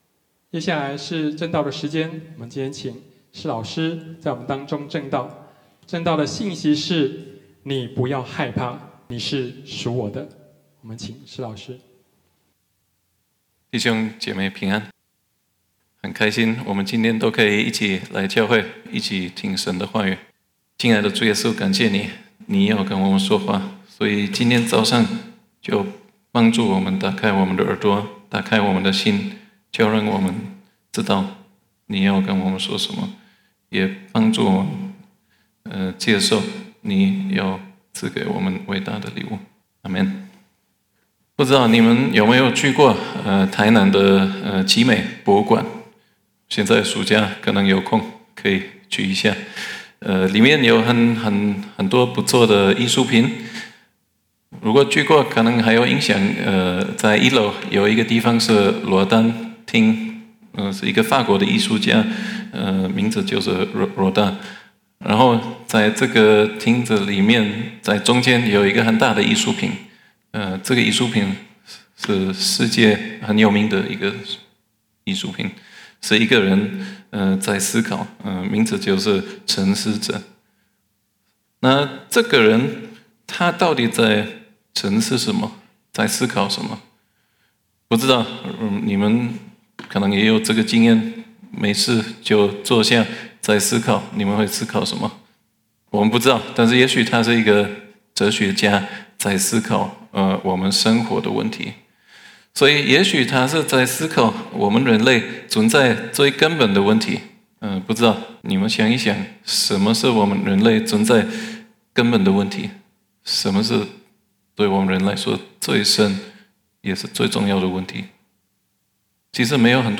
Posted in 主日信息